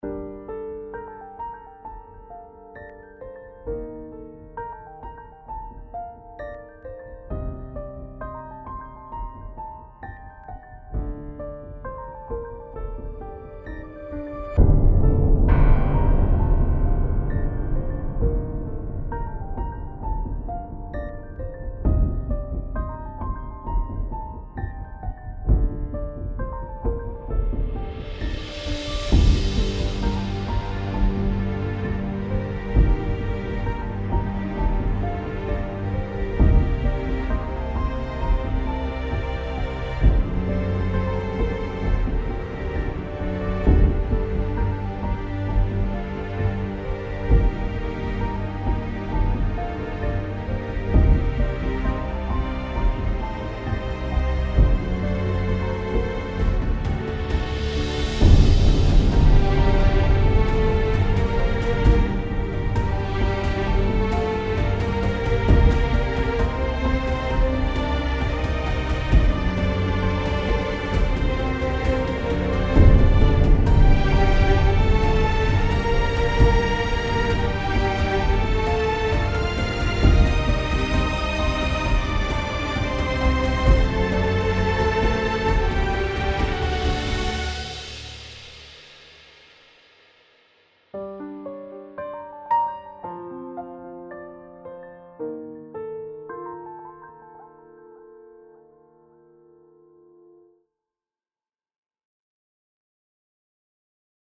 piano - epique - nappes - aerien - melodieux